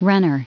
Prononciation du mot runner en anglais (fichier audio)
Prononciation du mot : runner